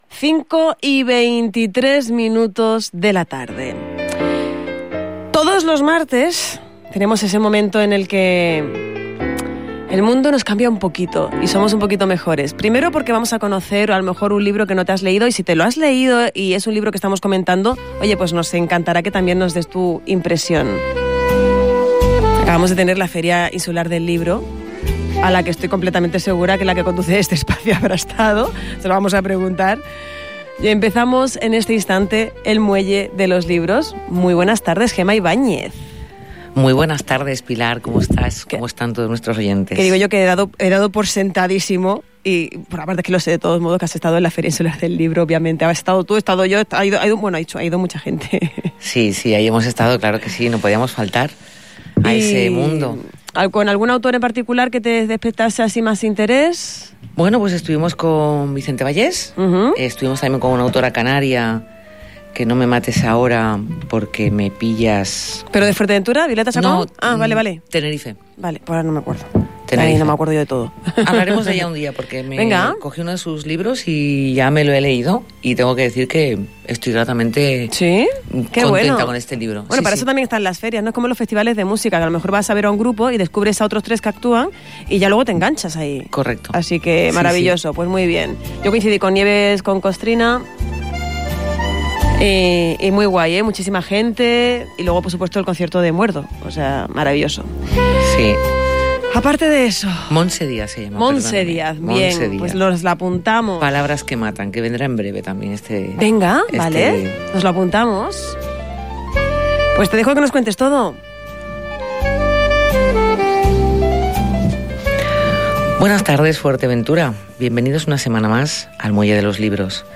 En esta edición de El Muelle de los Libros la gran protagonista ha sido la poesía, realzada por la música que caracteriza este espacio.